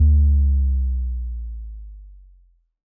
Bass Power Off 5.wav